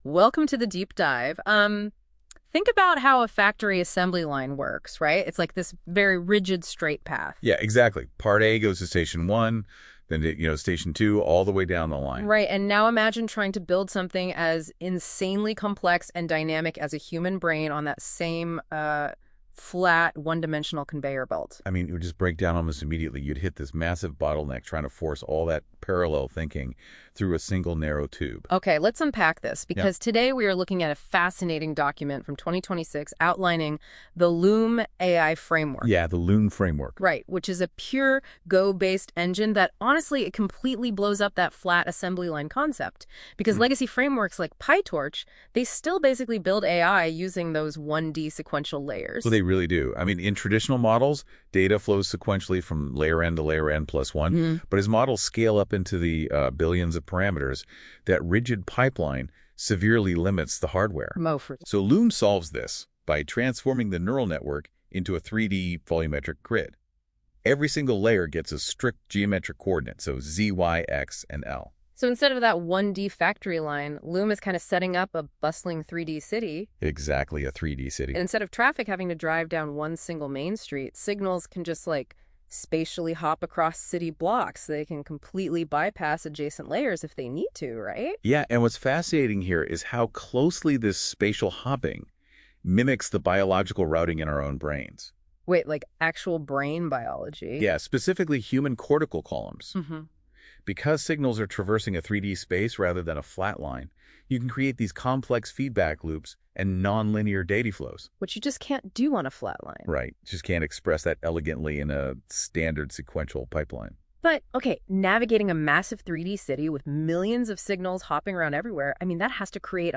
AI-Generated Deep Research Podcast
An AI-narrated walkthrough of the Loom architecture — from volumetric tensor dispatch to the paradigm shift away from backpropagation.